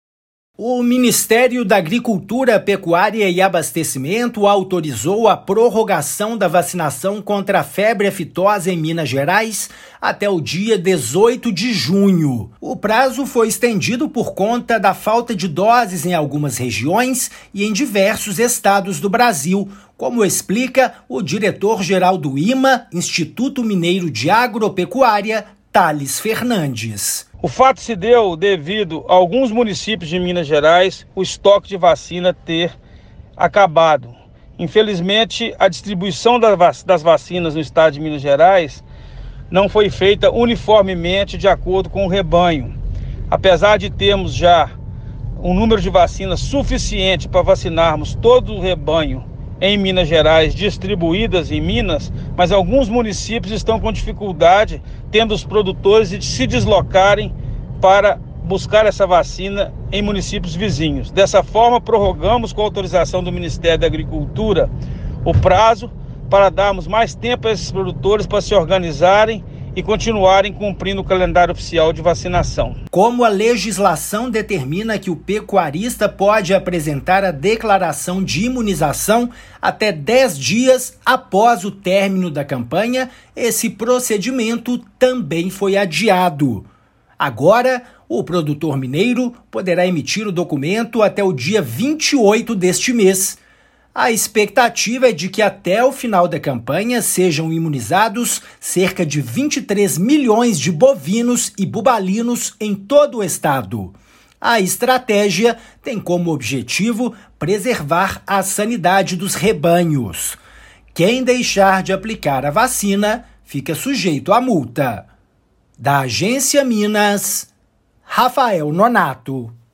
[RÁDIO] Ministério autoriza prorrogação da vacinação contra febre aftosa em MG
Pecuaristas poderão comprar a vacina até 18/6 em estabelecimento autorizado da iniciativa privada. Limite para enviar declaração ao IMA passa a ser 28/6. Ouça a matéria de rádio.